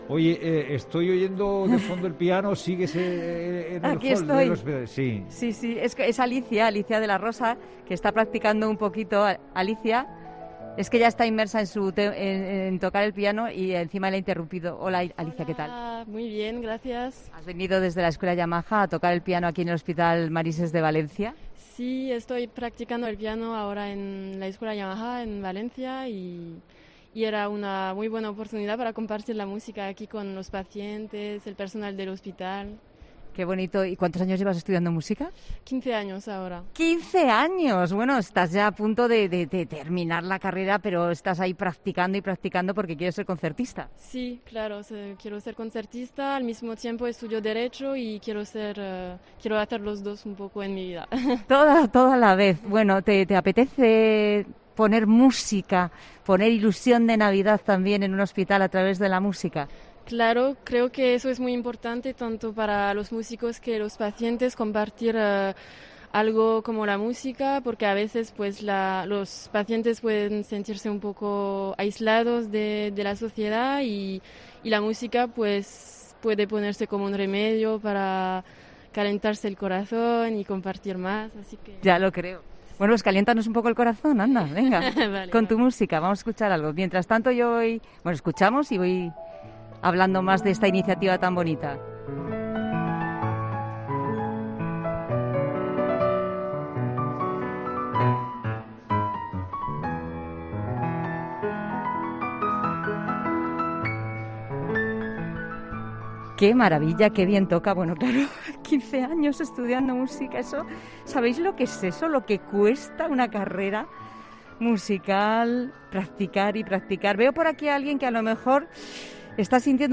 Este hospital ha tenido la iniciativa de poner un piano en el hall para que los pacientes y las familias desconecten de una situación que no es agradable para nadie como es la de estar en un hospital.